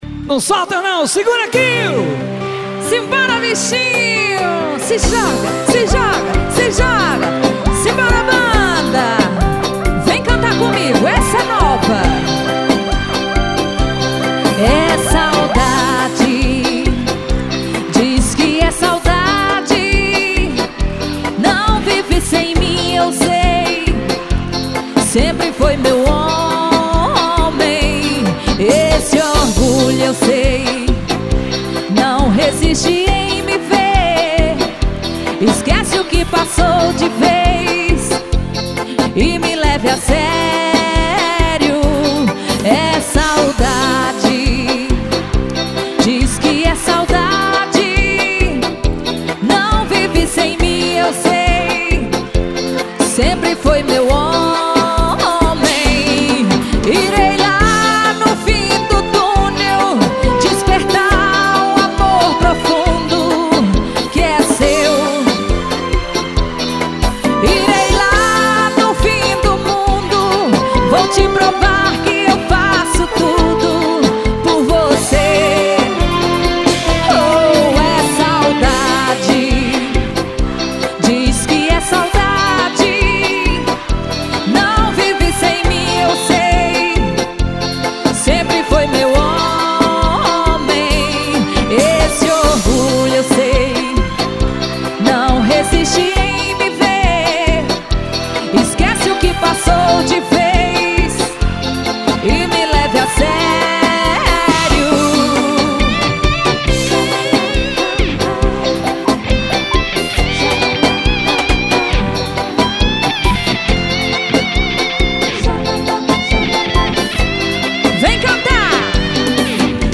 na pegada do arrocha